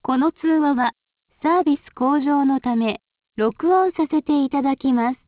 録音告知 固定アナウンス